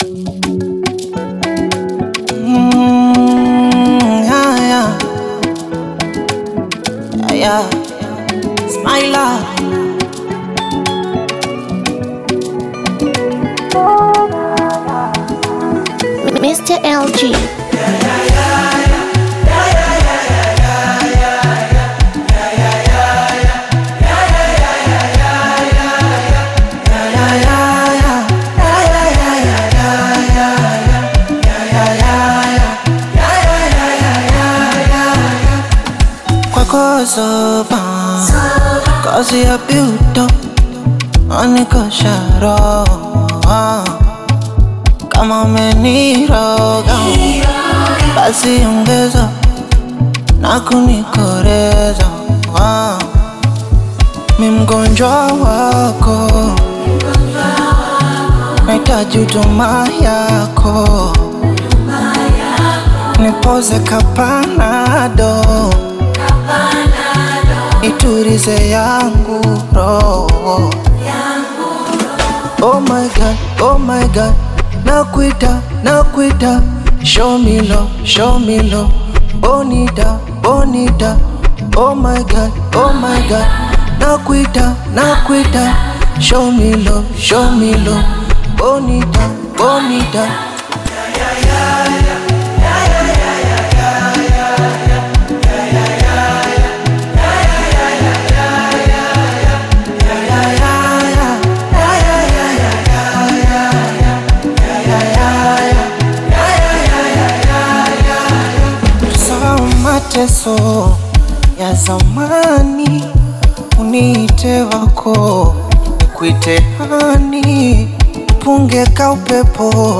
Tanzanian Bongo Flava